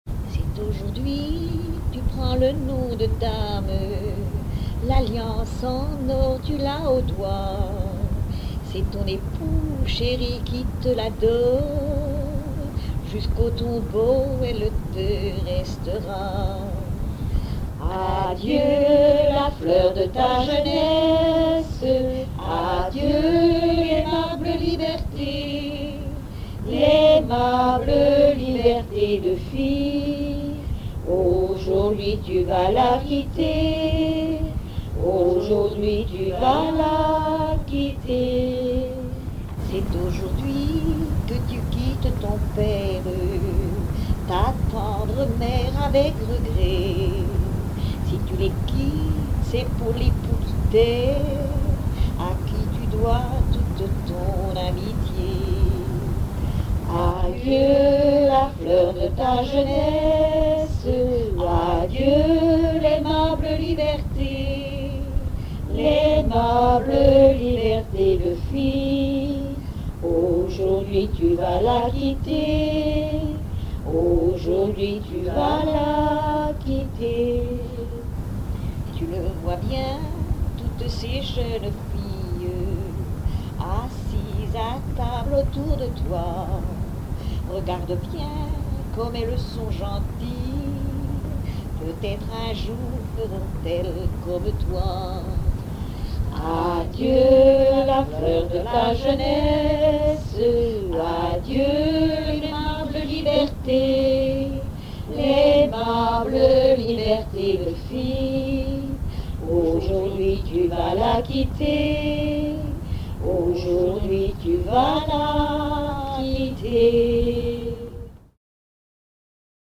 circonstance : fiançaille, noce ;
Genre strophique
Catégorie Pièce musicale inédite